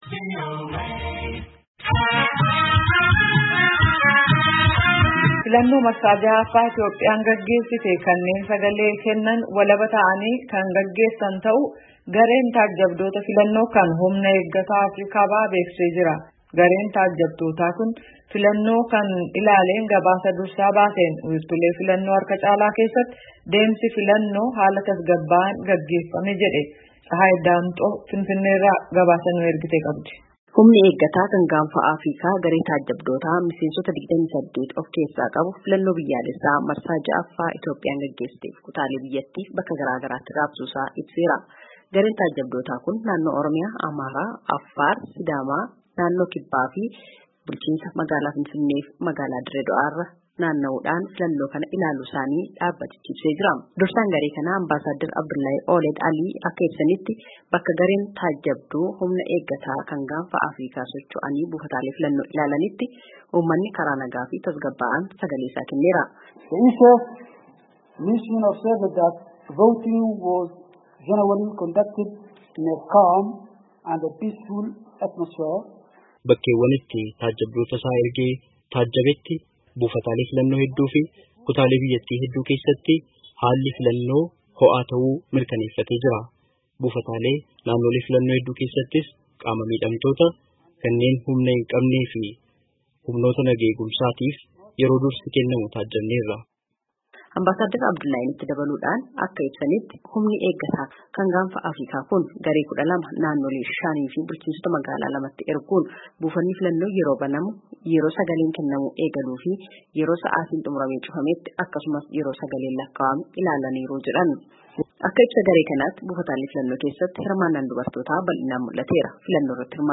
Finfinnee irraa gabaase.